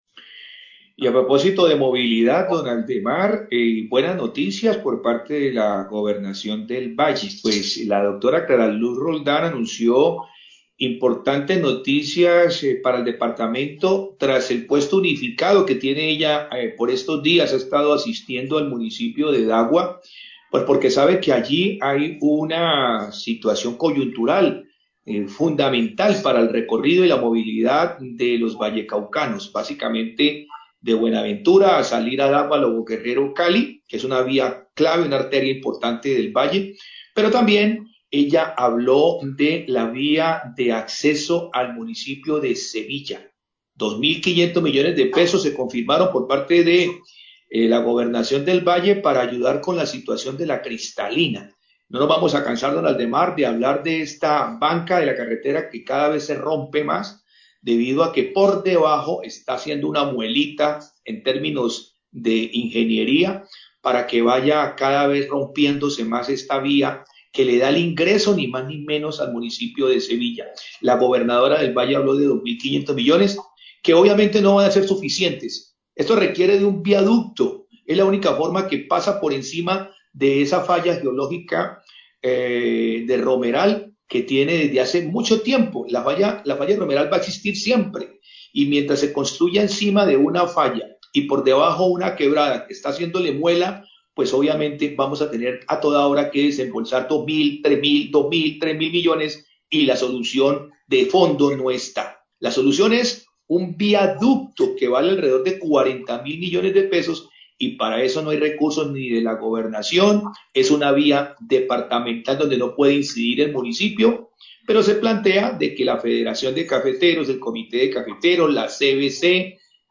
Radio
Periodista manifiesta que los recursos no son suficientes ya que las obras que se deben realizar en el sector tienen que ser más profundas ya que la vía pasa sobre una falla geológica.